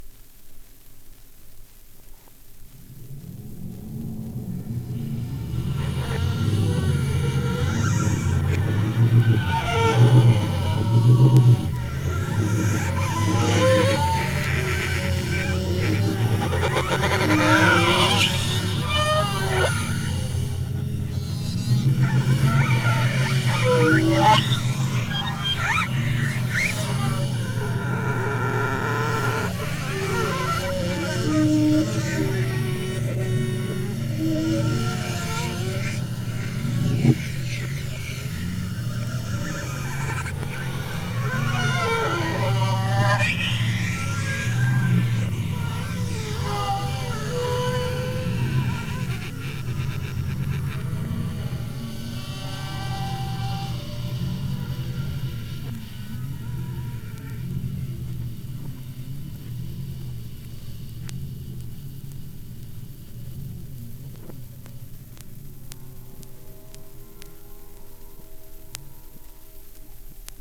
vorwärts und rückwärts
1A1 01 bow on bow sextet, turn table (16 Kanal stereo) 17.03